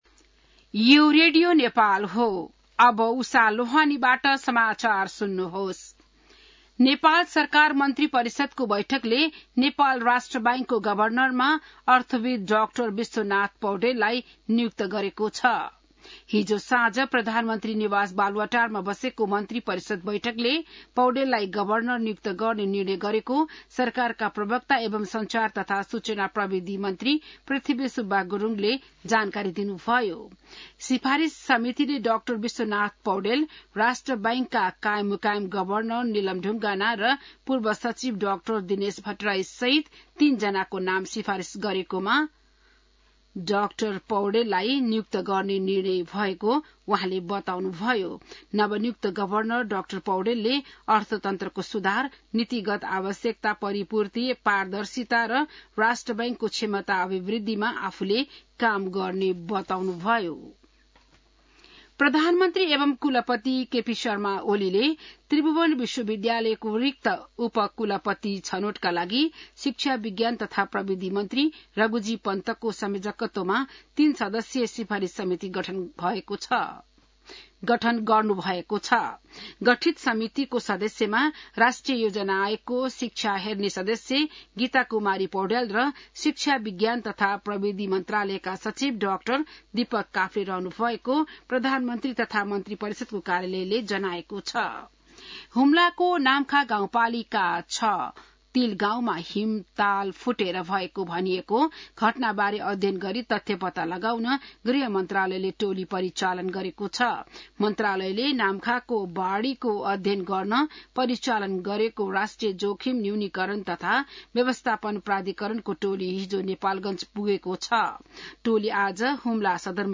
बिहान १० बजेको नेपाली समाचार : ७ जेठ , २०८२